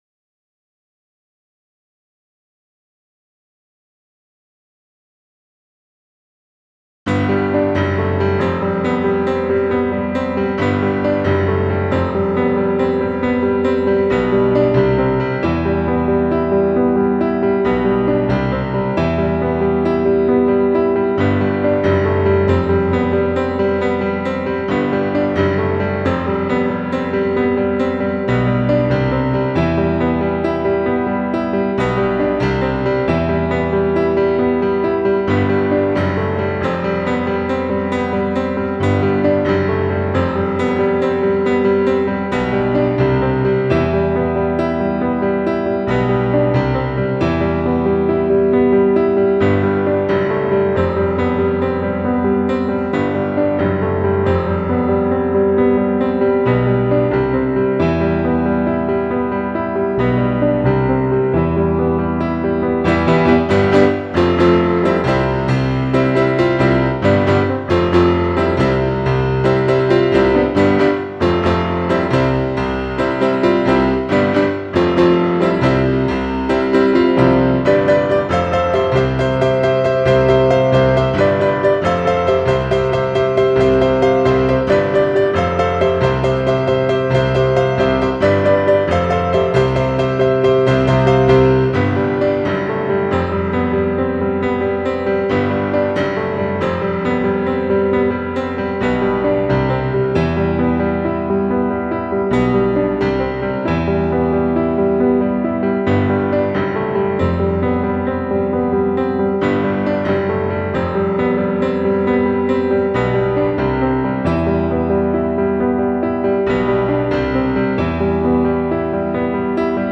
StienwayPiano2.wav